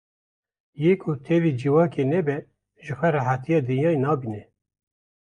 /ʒɪxˈwɛ/